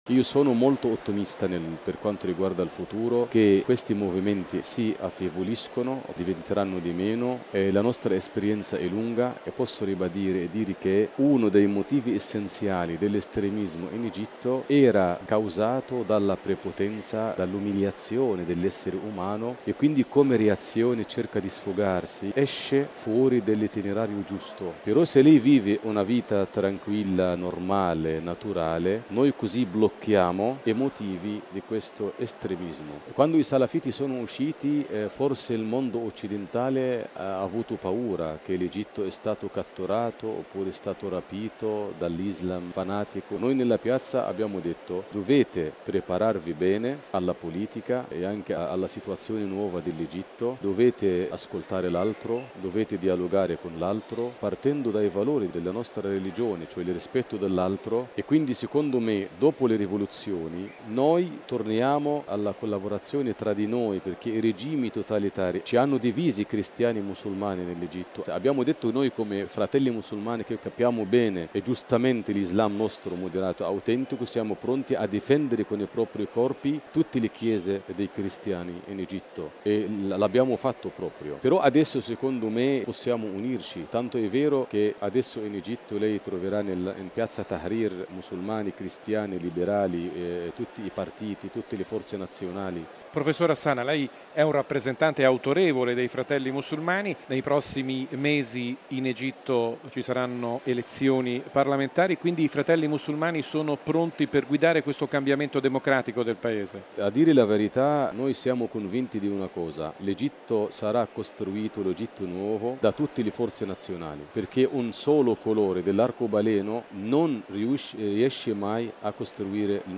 ◊   Al Meeting per l’Amicizia dei Popoli in corso a Rimini si è parlato ieri di una delle crisi che hanno attraversato il mondo arabo: quella in Egitto, Paese ormai proiettato verso le prime elezioni libere.